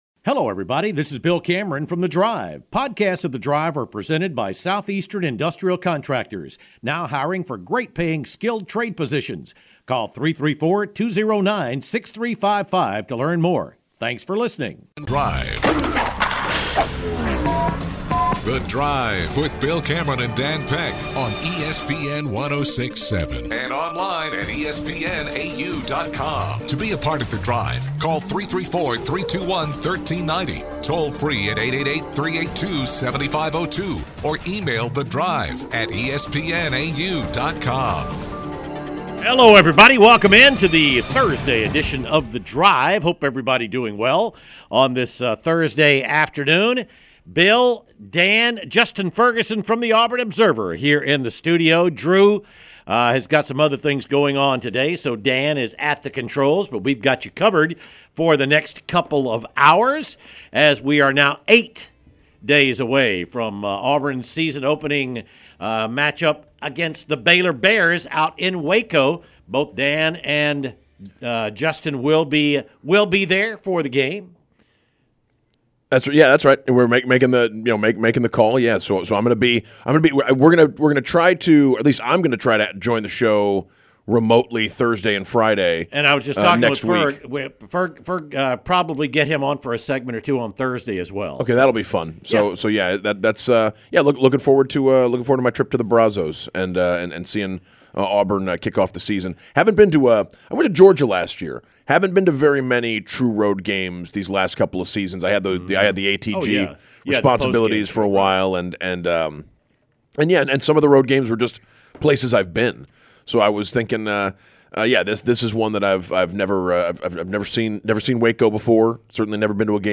Callers outline their expectations for the upcoming season.